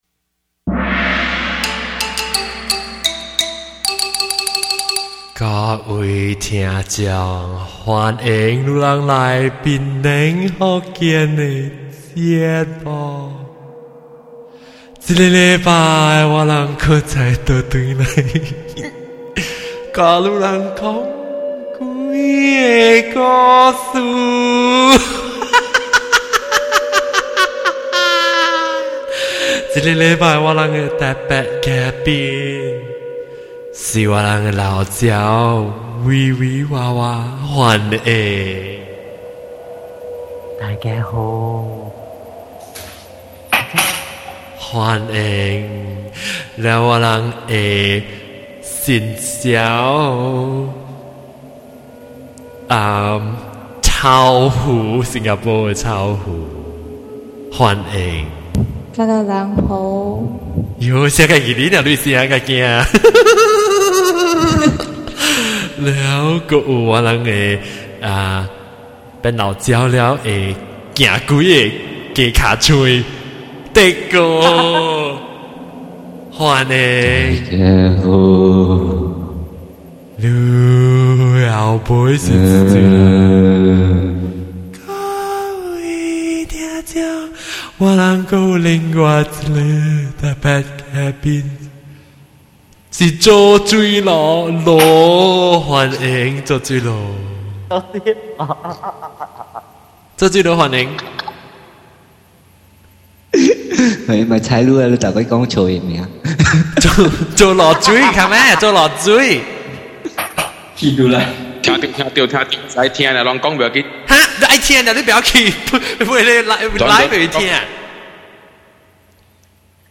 ATTENTION: I really heard a kid's voice on 42:29. Tell me if you can hear it, and if it's our guest's voice?